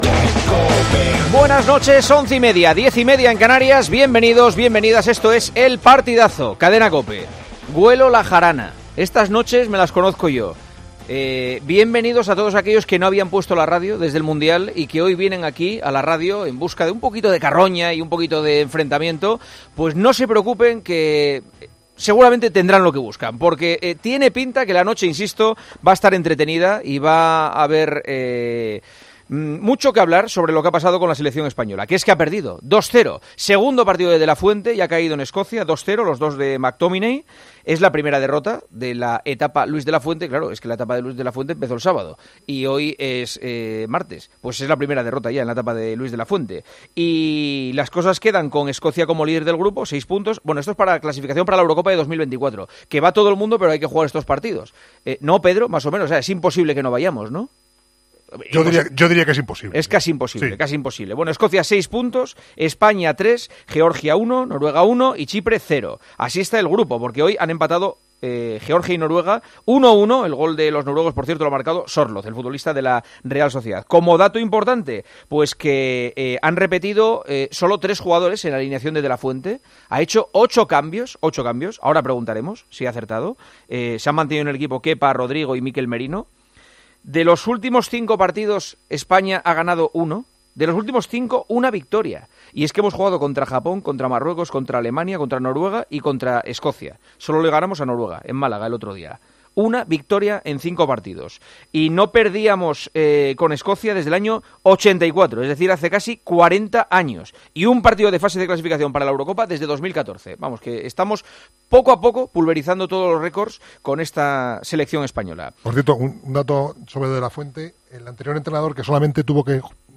Con estas palabras, Juanma Castaño daba entrada a la edición de martes de El Partidazo de COPE, en el día en que la Selección Española dirigida por Luis de la Fuente sumó su primera derrota.